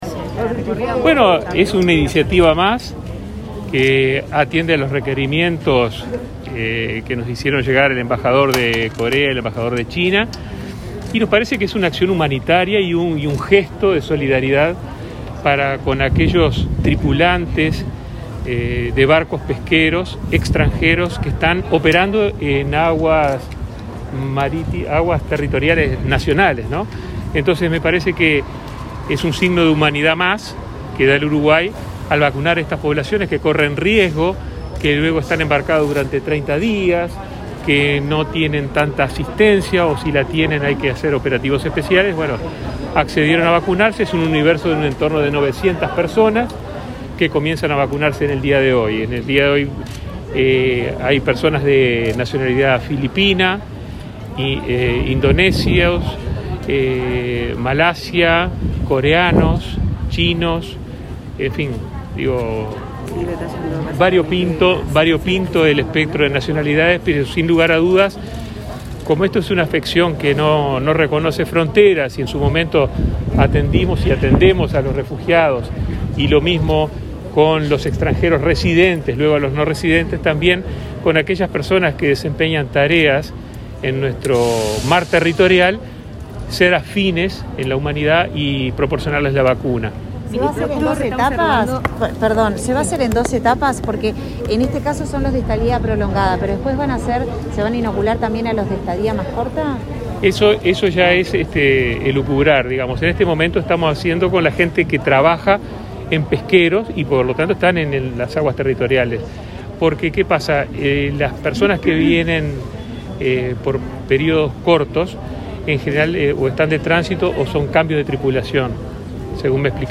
Declaraciones a la prensa del ministro de Salud Pública, Daniel Salinas
Declaraciones a la prensa del ministro de Salud Pública, Daniel Salinas 07/09/2021 Compartir Facebook X Copiar enlace WhatsApp LinkedIn Este martes 87, el ministro de Salud Pública, Daniel Salinas, presenció la primera jornada de vacunación contra la COVID-19, a los tripulantes de barcos pesqueros extranjeros que están atracados en el Puerto de Montevideo y, luego, dialogó con la prensa.